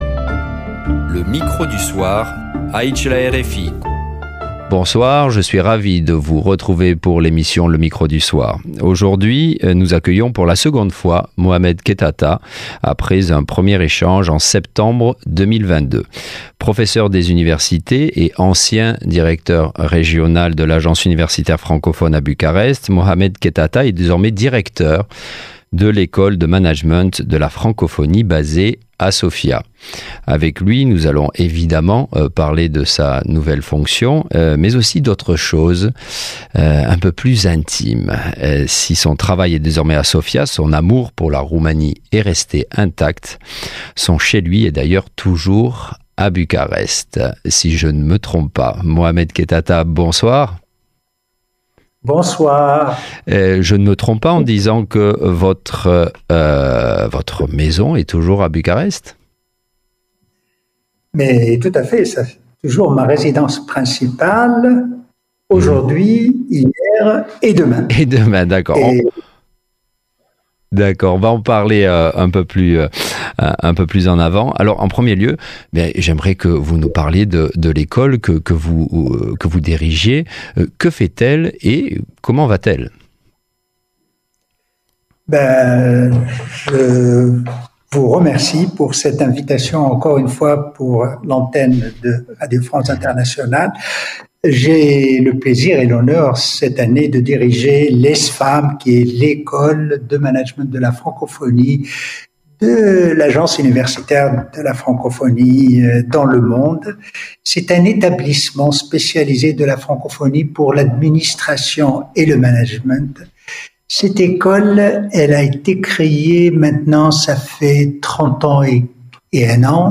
Al doilea interviu